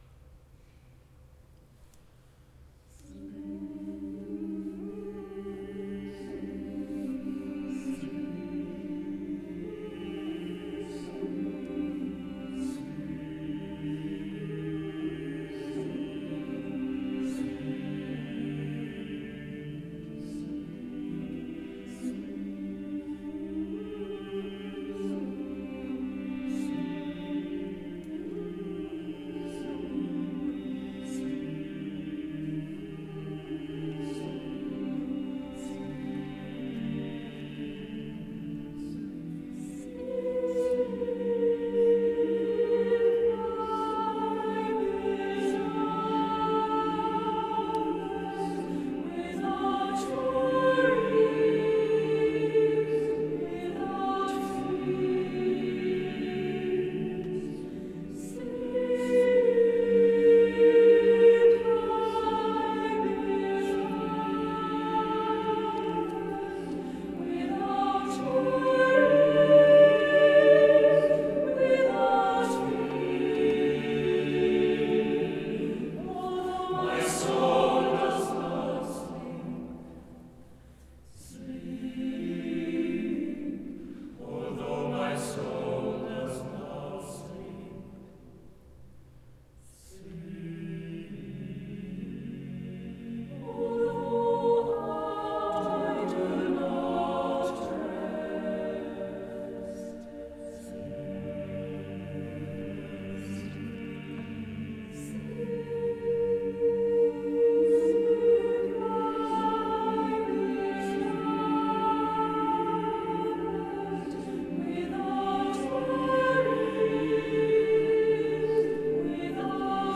Choral music
Mixed choir / durata 4 min / text by Gabriela Mistral / premiered and commissioned by Luxensemblen.
Sleep-my-beloved-live-in-Engelbrektkyrkan.mp3